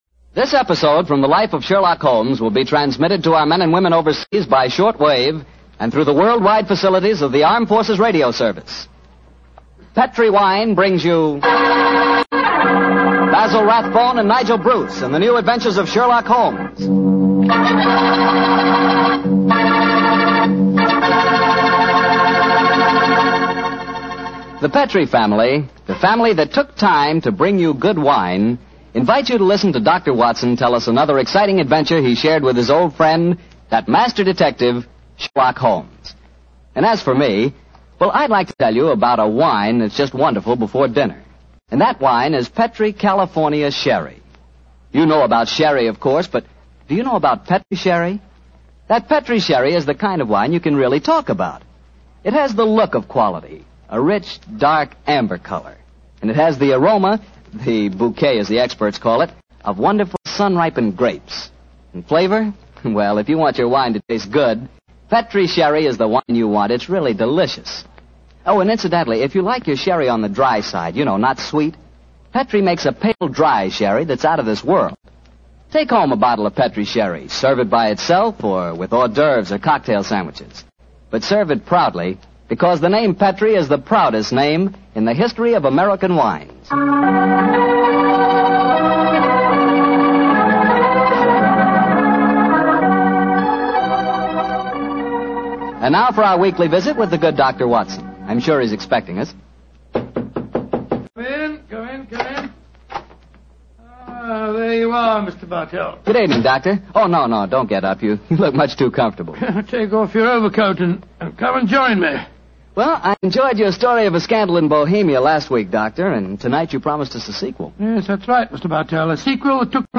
Radio Show Drama with Sherlock Holmes - The Second Generation 1945